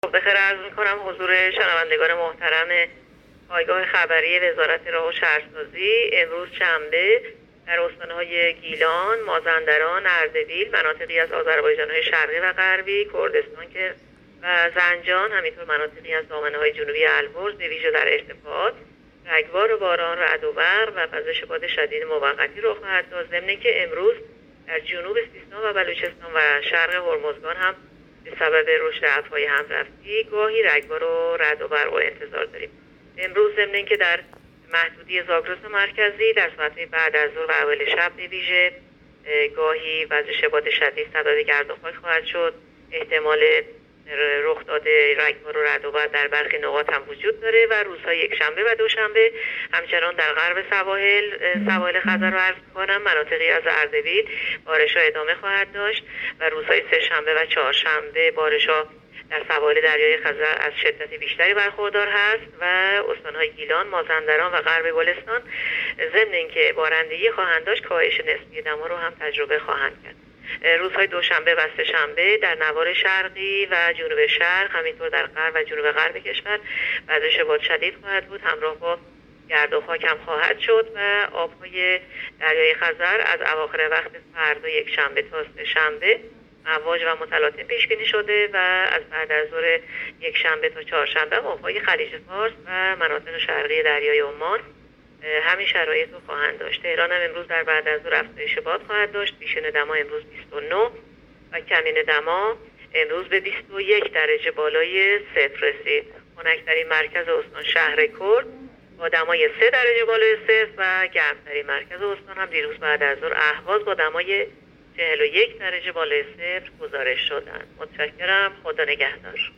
گزارش رادیو اینترنتی پایگاه‌ خبری از آخرین وضعیت آب‌وهوای ۵ مهر؛